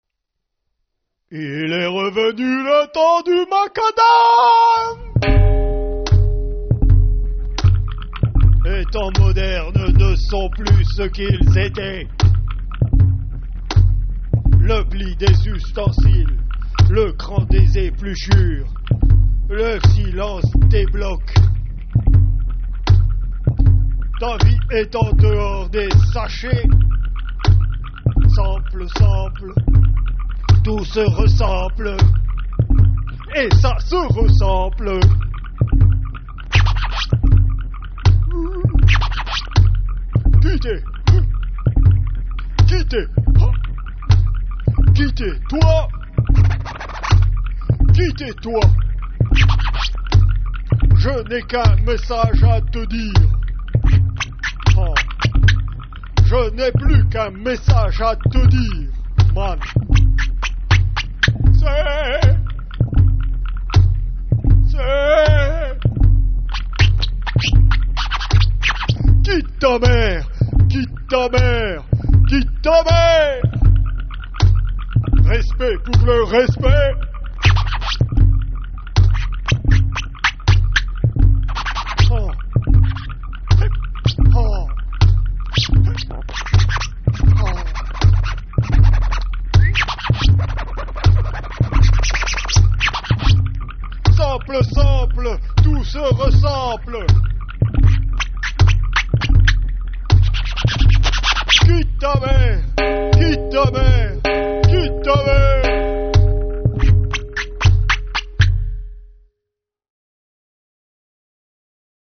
Un-pop-hilarity Singing.